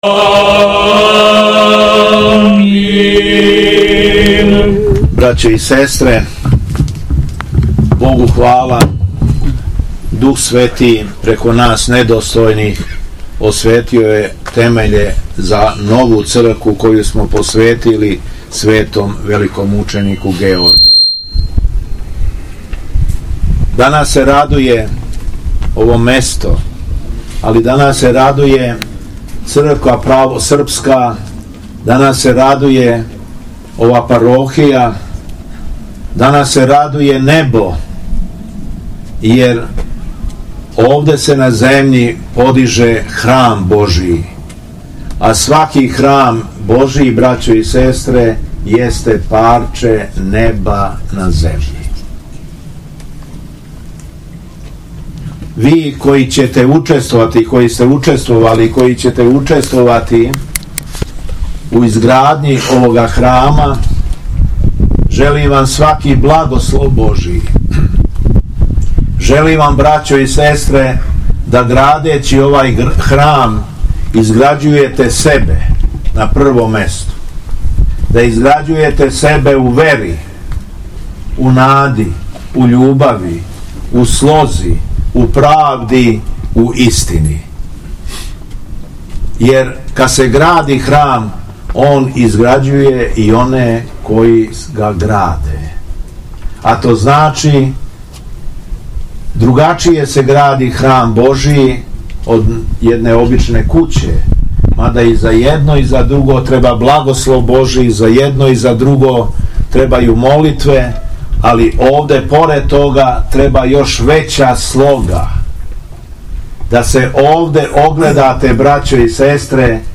Беседа Његовог Преосвештенства Епископа шумадијског г. Јована у Мисачи